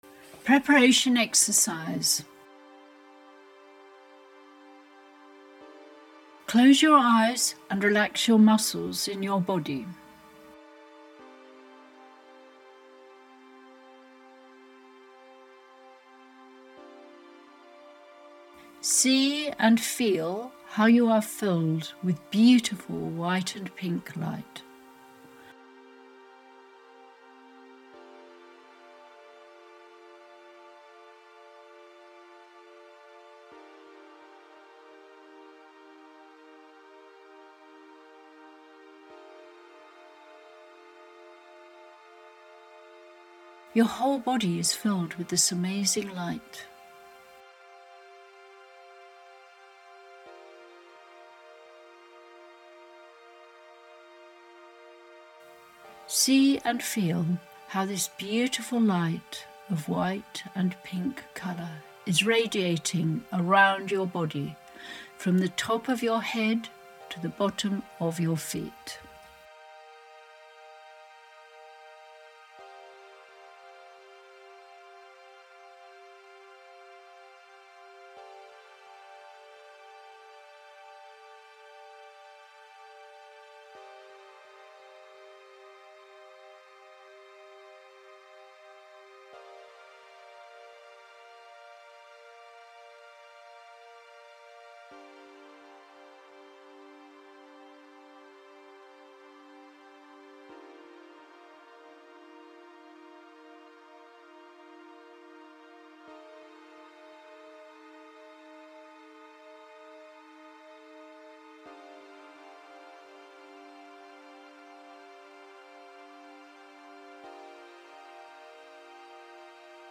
2 FREE GUIDED MEDITATIONS